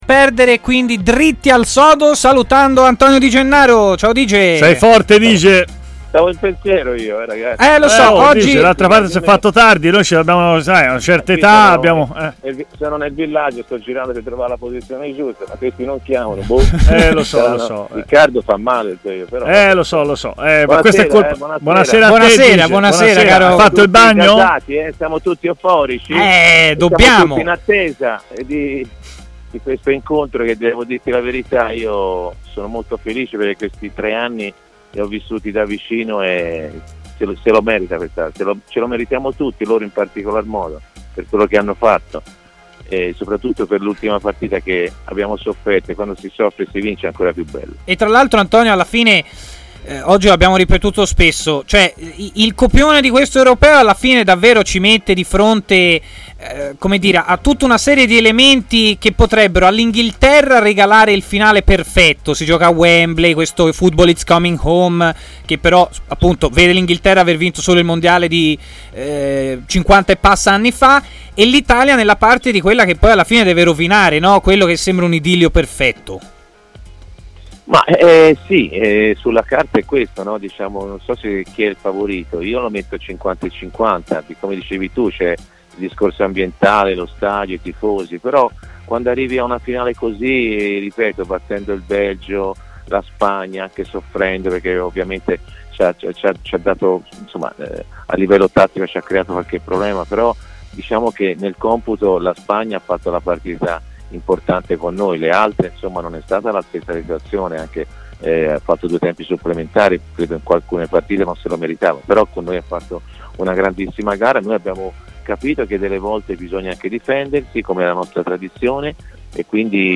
L'ex centrocampista Antonio Di Gennaro, opinionista di TMW Radio, è intervenuto in diretta durante Stadio Aperto